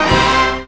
JAZZ STAB 25.wav